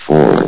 four.ogg